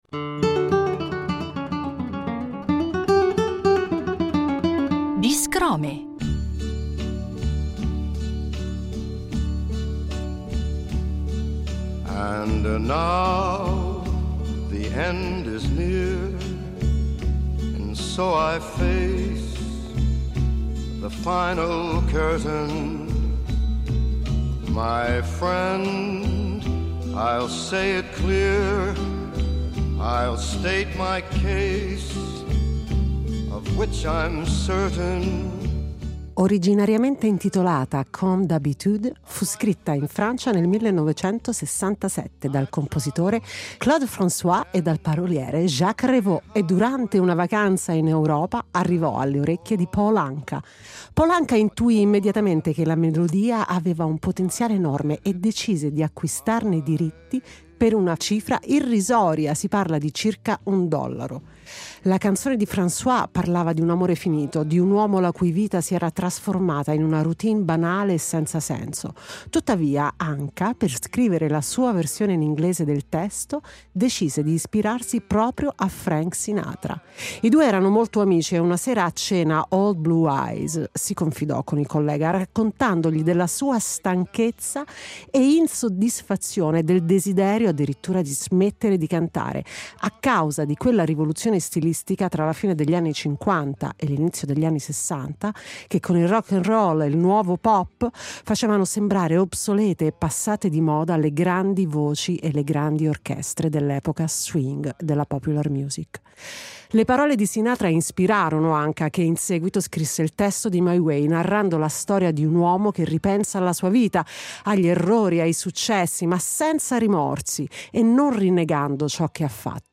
con la cantante, musicista e compositrice
un tributo in chiave jazz